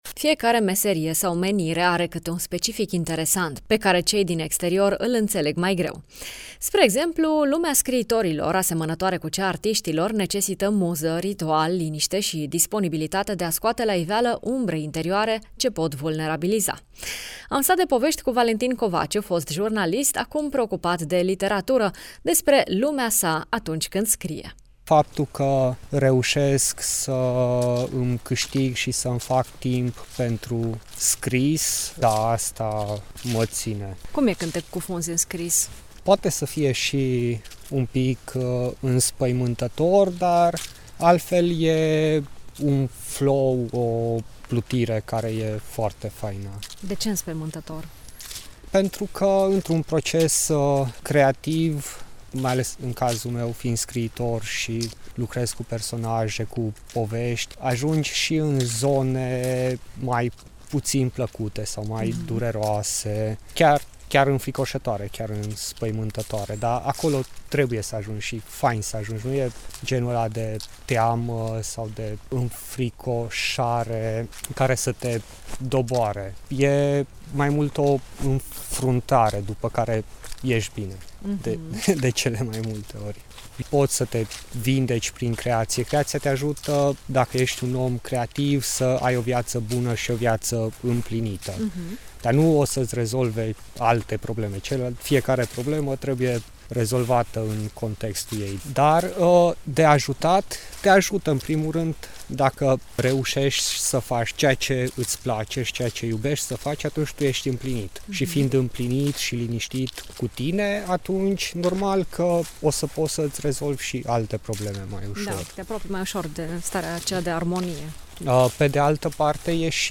scriitor